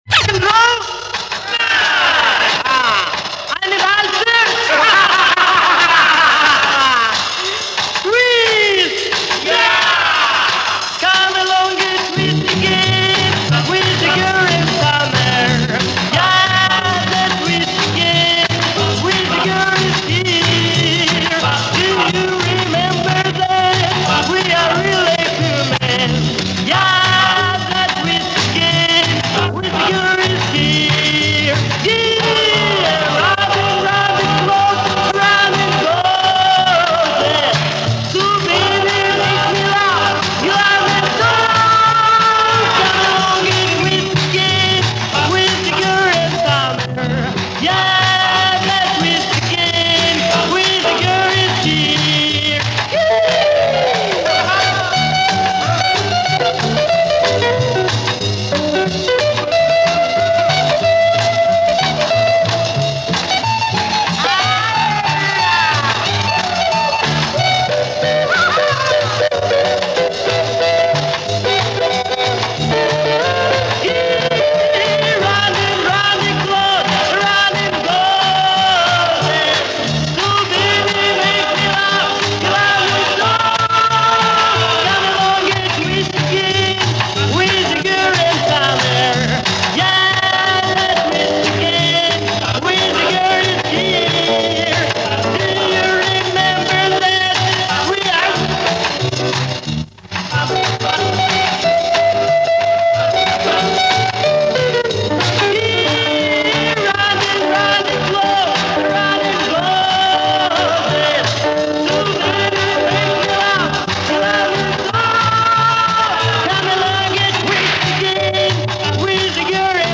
У меня сохранился фрагмент этой записи с телевизора .Неоднократно выставлял на опознание здесь -но пока безрезультатно...Единственная зацепка- это живое выступление -певец поперхнулся посреди песни .Ну и в конце в такт песне-револьверные выстрелы.Записывал в 1970 году .Вот пожалуй и все...